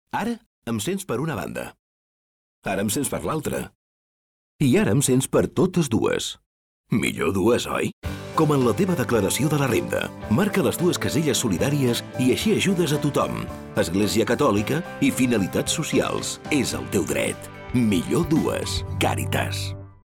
Falca_radio_Catala_Millor_2.mp3